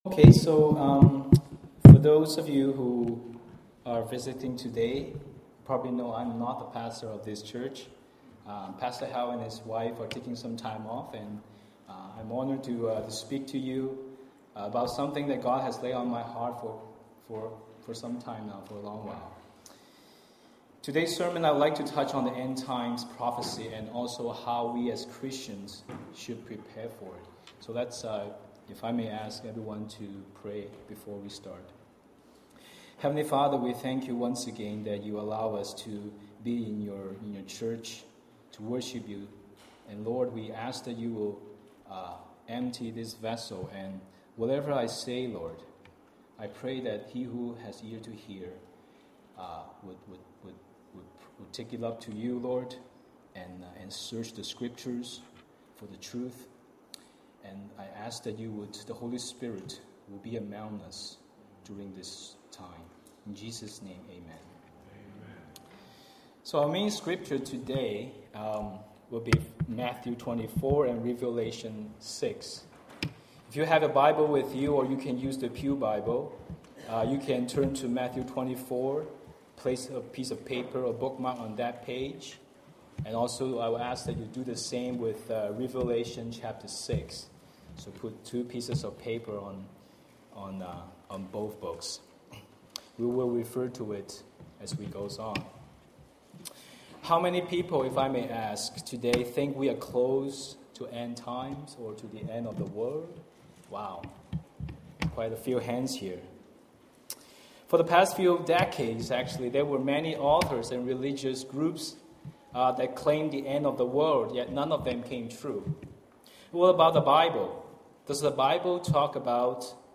July 27 KCC Sermon.mp3